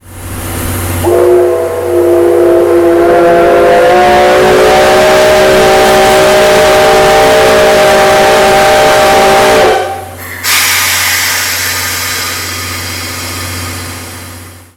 8732レこだまする汽笛を残して D51498, C6120 （水上−上牧；水上駅発車）
C6120whistle.mp3